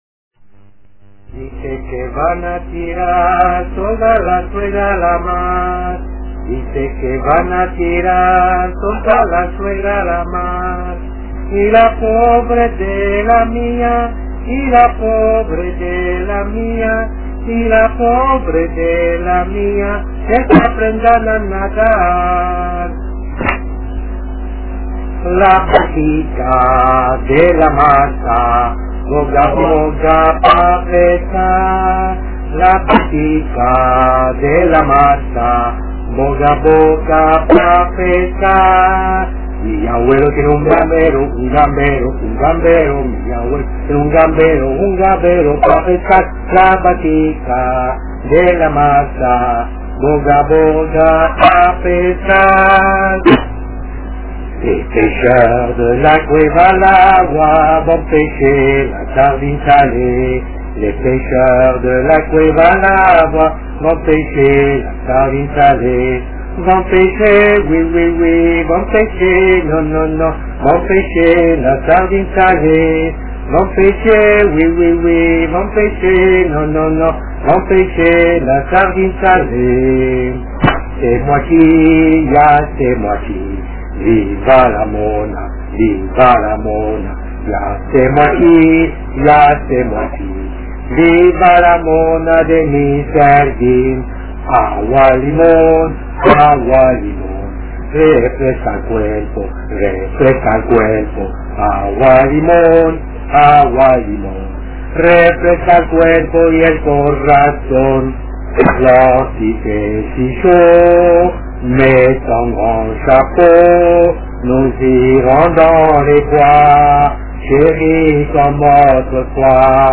EN ACAPELA